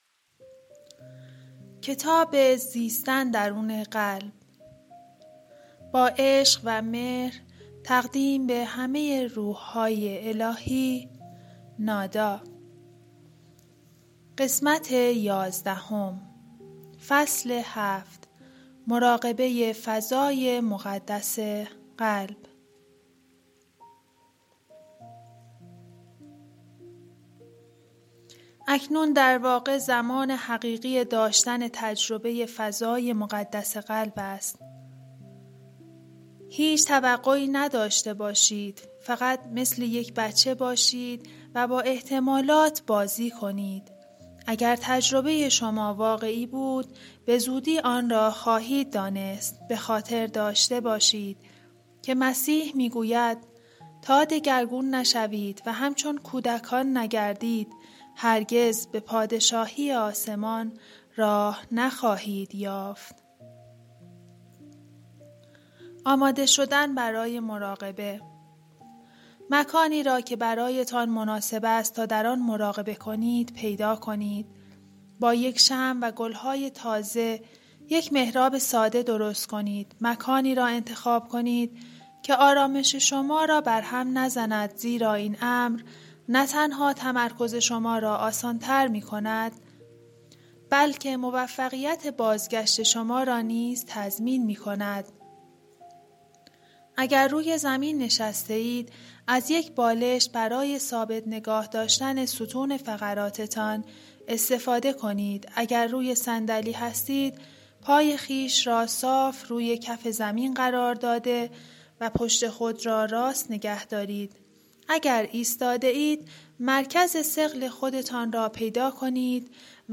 کتاب گویای زیستن درون قلب نویسنده درونوالو ملچیزدک / قسمت11